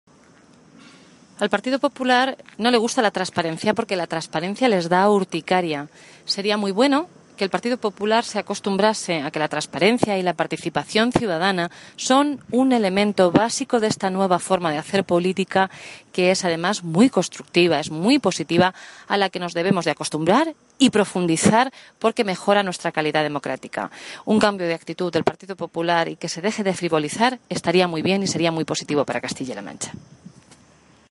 La portavoz del Grupo Parlamentario Socialista, Blanca Fernández, ha indicado esta mañana en Toledo que al Partido Popular le da urticaria que los miembros del Consejo de Gobierno comparezcan en las Cortes de Castilla-La Mancha para informar de su situación patrimonial y económica, así como de las actividades profesionales desarrolladas en los últimos cinco años.
Cortes de audio de la rueda de prensa